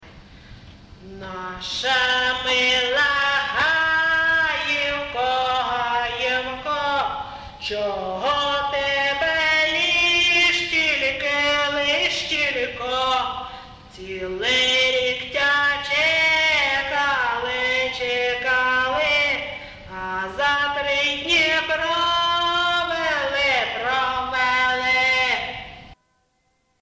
ЖанрВеснянки, Ігри
Місце записус. Ковалівка, Миргородський район, Полтавська обл., Україна, Полтавщина